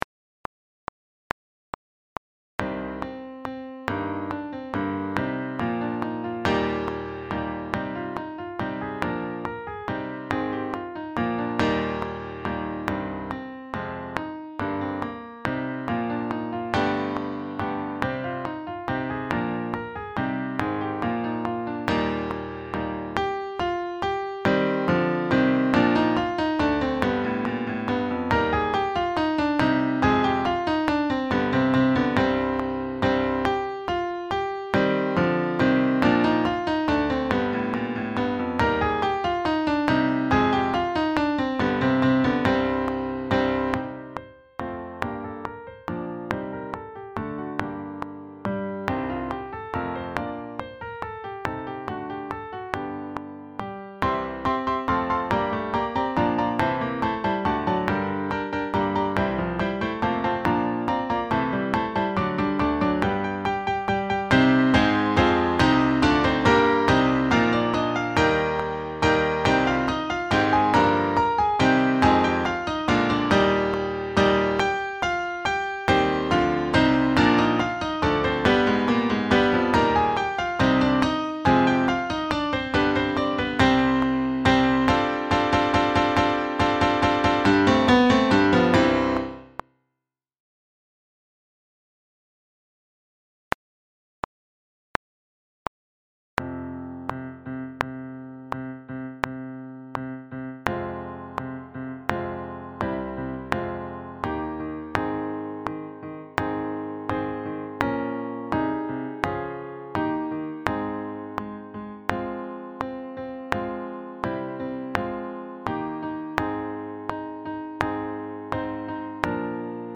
Sax Nonets
sSAAATTBbDuration:
Backing track